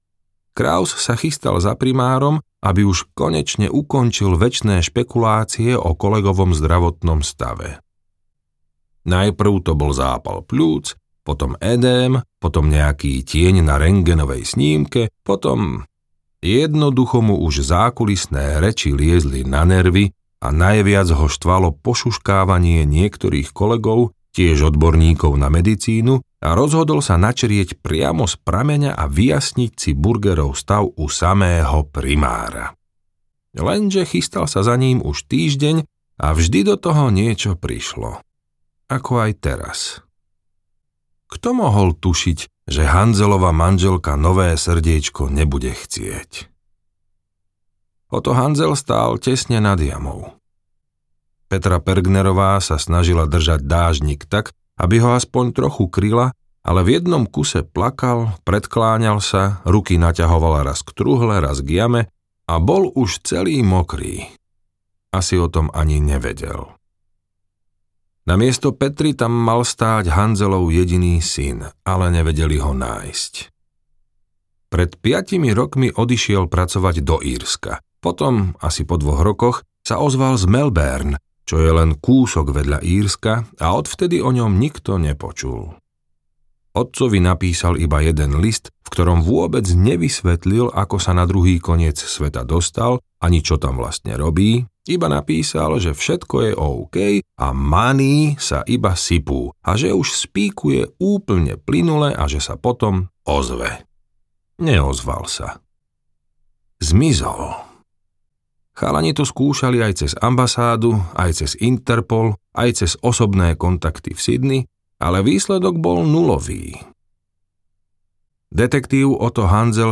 Kožené srdce audiokniha
Ukázka z knihy